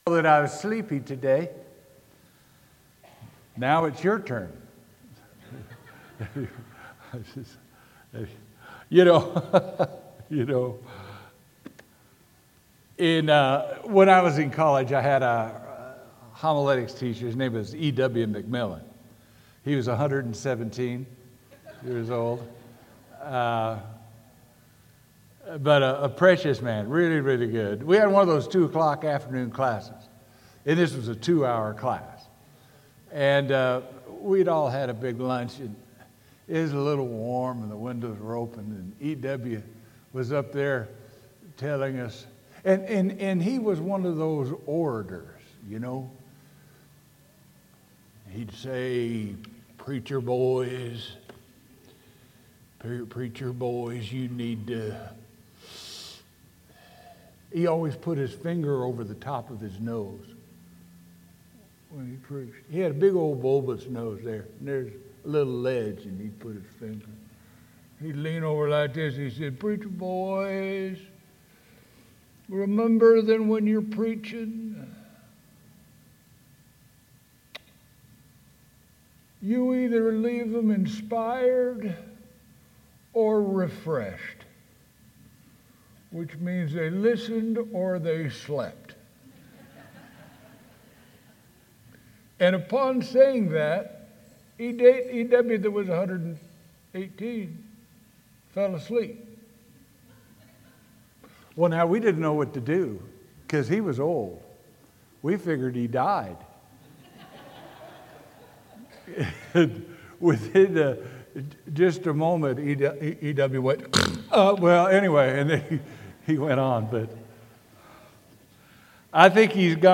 Sermon: Dr. Luke “At Jesus’ Feet”